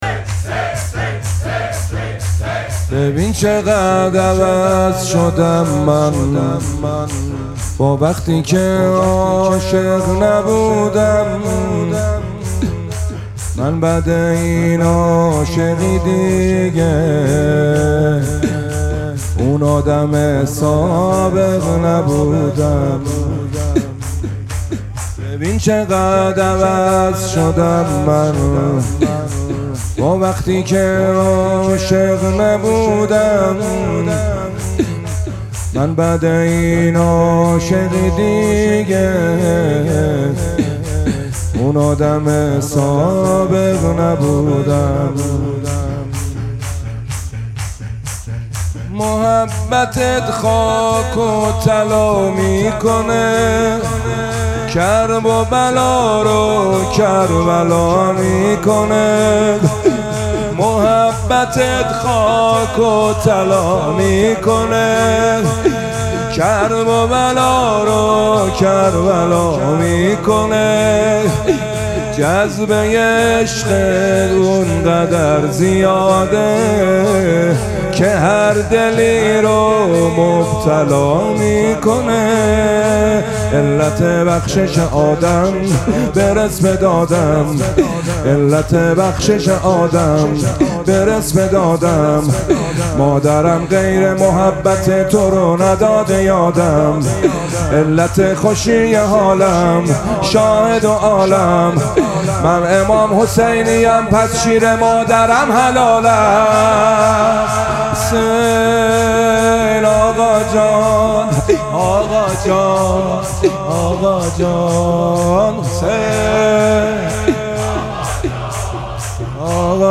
مراسم مناجات شب ششم ماه مبارک رمضان
شور
مداح